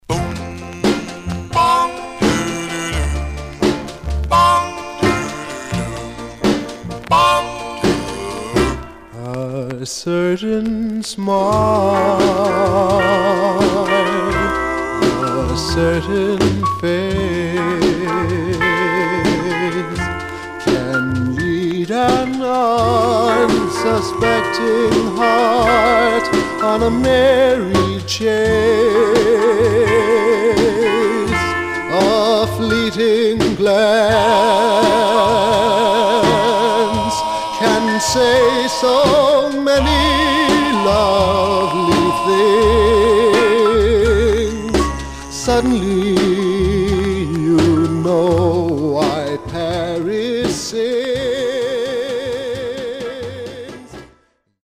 Surface noise/wear Stereo/mono Mono
Male Black Groups